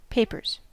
Ääntäminen
Ääntäminen US Haettu sana löytyi näillä lähdekielillä: englanti Papers on sanan paper monikko.